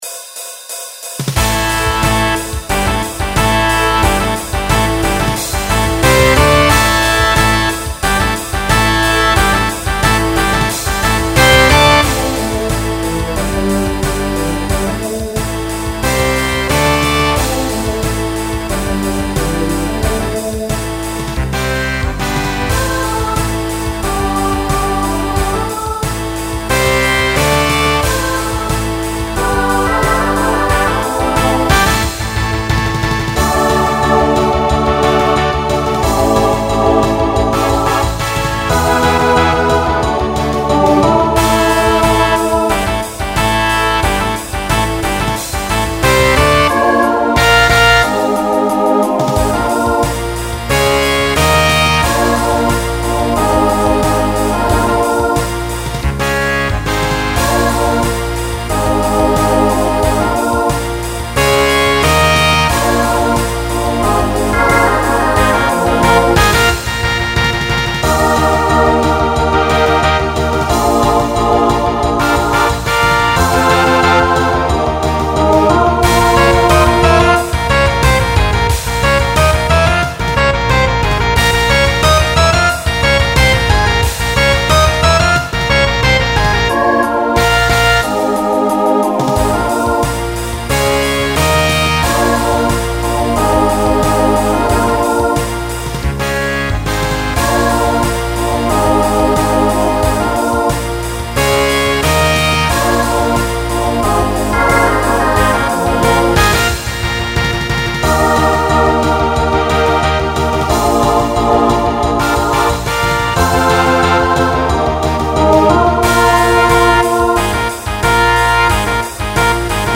Genre Rock Instrumental combo
Story/Theme Voicing SATB